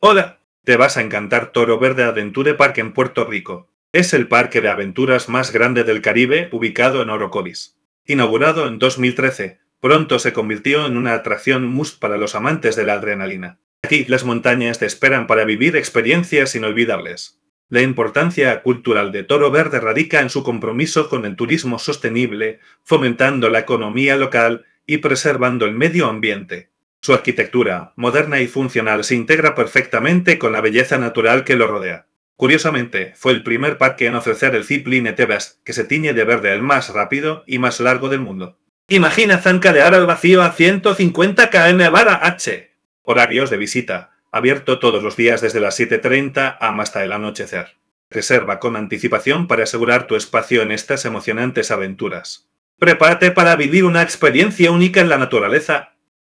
karibeo_api / tts / cache / 0a795d478ebb67fa06ed141341f26bf5.wav